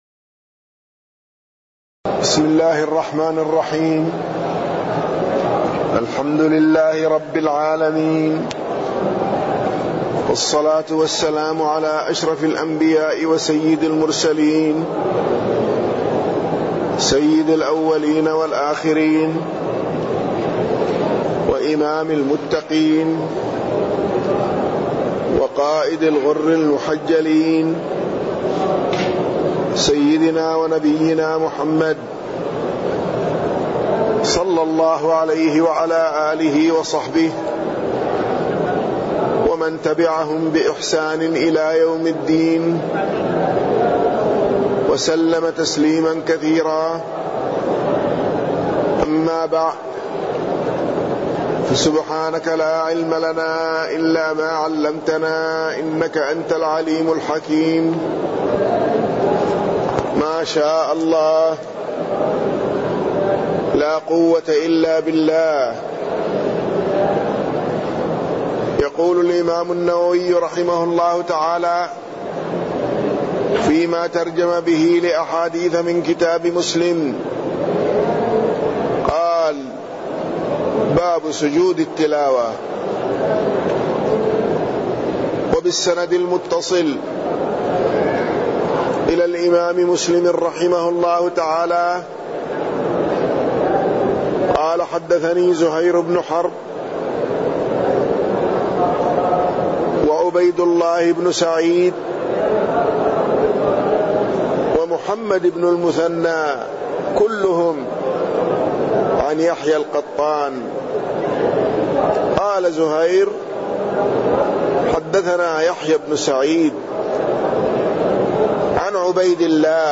تاريخ النشر ٢٦ رجب ١٤٢٩ هـ المكان: المسجد النبوي الشيخ